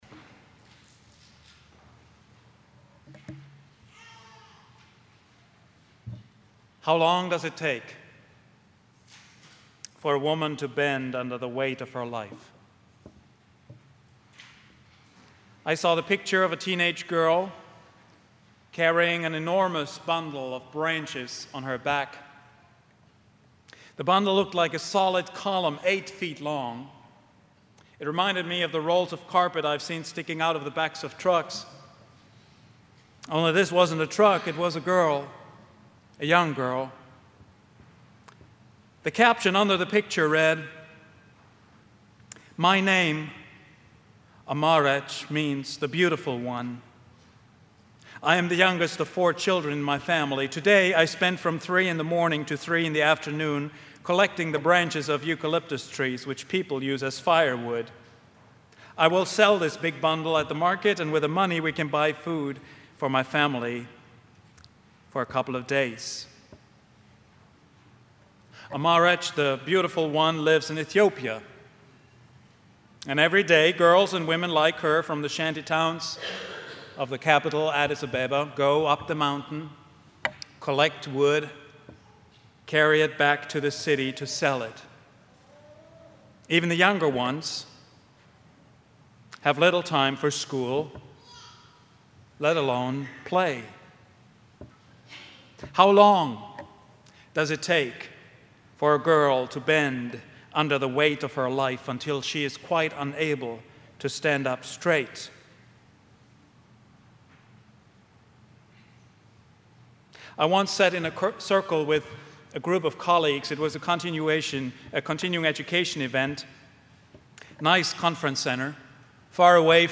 Sabbath Peace — Vine Street Christian Church